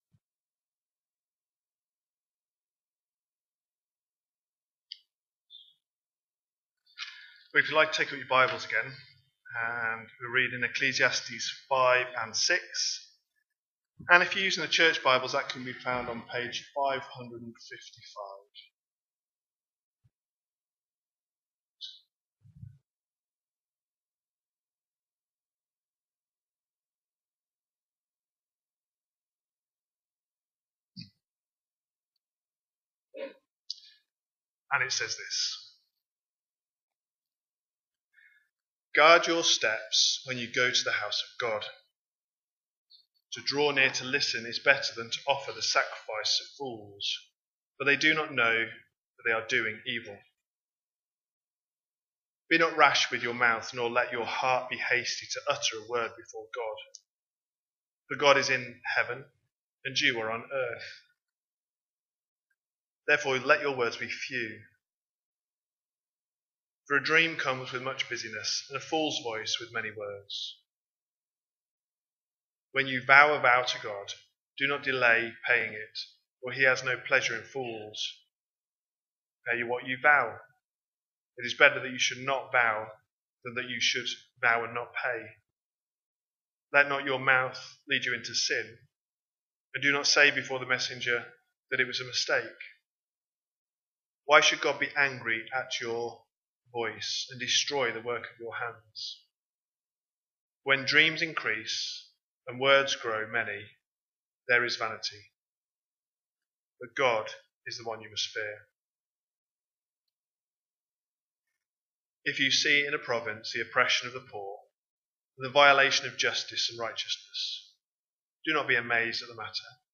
Sermons from Trinity Church Bradford
The latest sermons from Trinity Church Bradford.